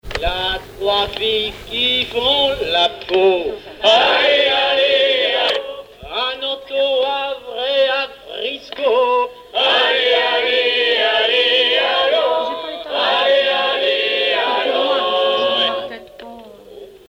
stage organisé par Jolie Brise à Fécamp
Fonction d'après l'informateur gestuel : à haler ; Usage d'après l'analyste circonstance : maritimes
Genre strophique
Pièce musicale inédite